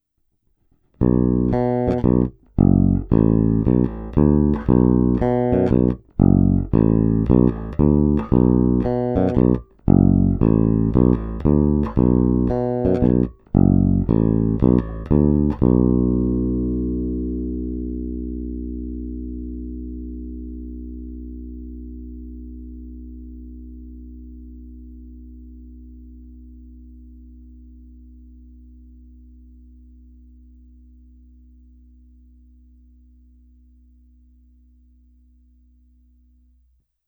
Není-li uvedeno jinak, jsou ukázky nahrány rovnou do zvukové karty a jen normalizovány. Hráno vždy nad aktivním snímačem, v případě obou pak mezi nimi.
Snímač u kobylky